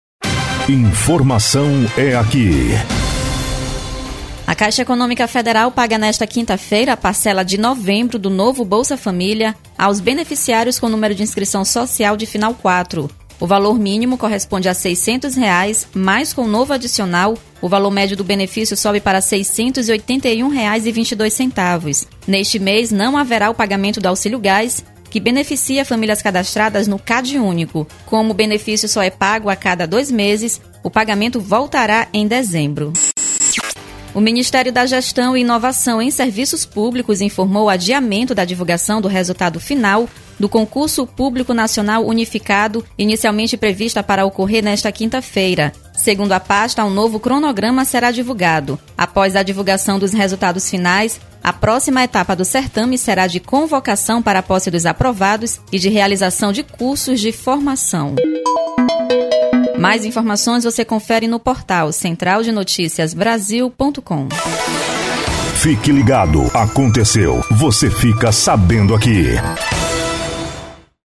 Todos os Plantões de Notícias